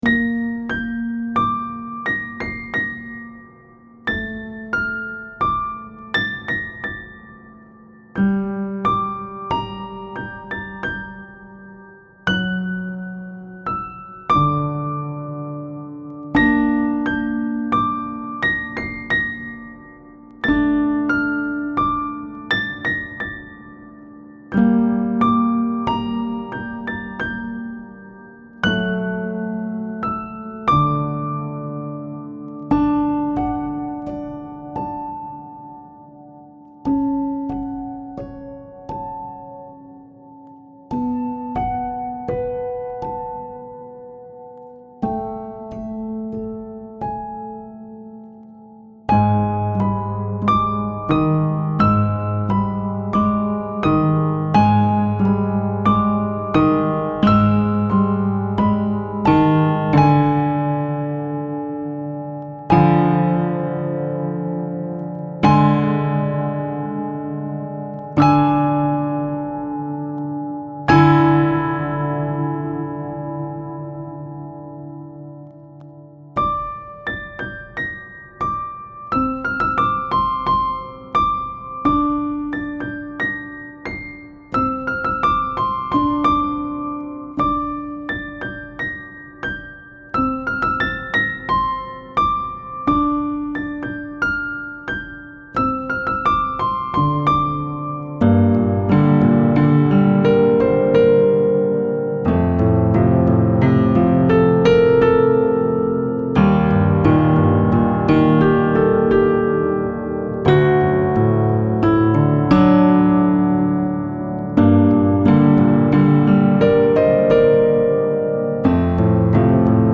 Style Style Folk
Mood Mood Intense, Mysterious
Featured Featured Piano
BPM BPM 88